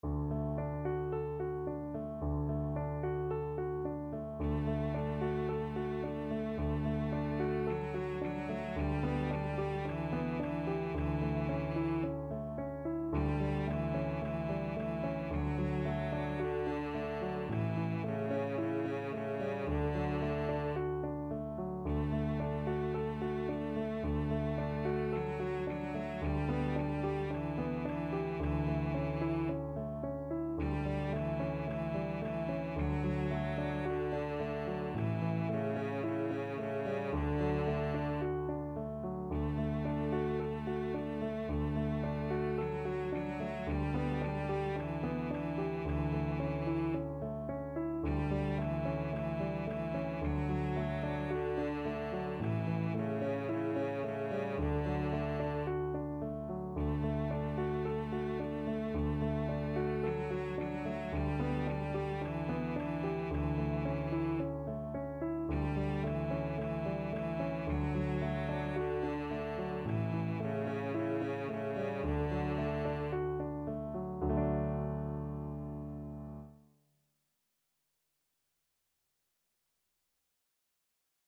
Allegro Moderato =c.110 (View more music marked Allegro)
Traditional (View more Traditional Cello Music)